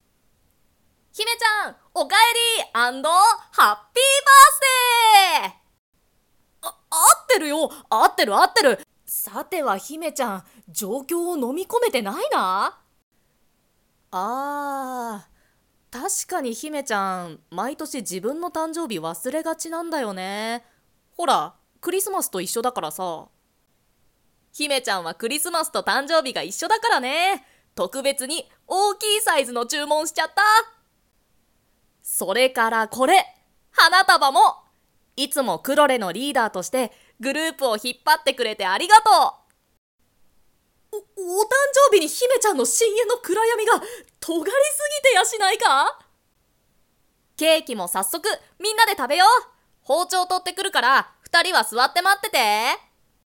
🩷バースデー声劇 💚